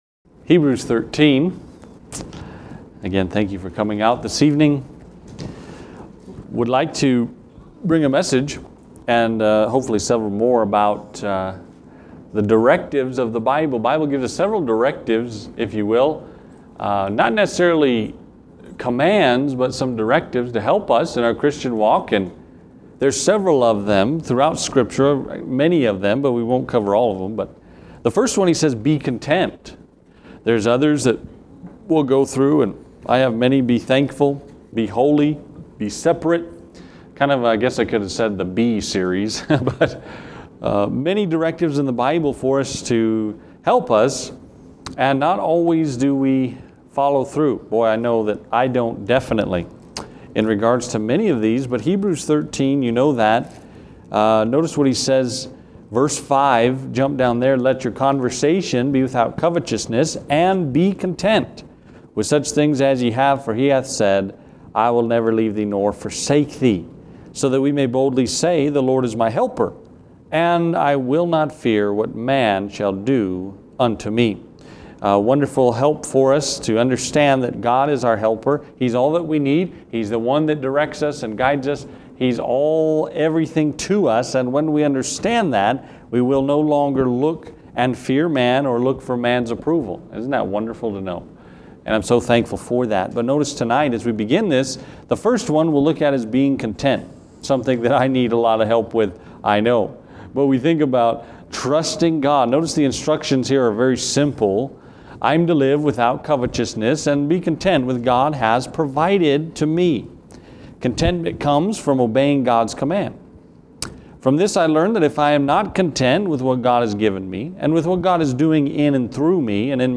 While the world teaches covetousness and a lack of content, the Bible again and again reminds us to be content. Hebrews 13:5-6 is our focus in this lesson on this important topic!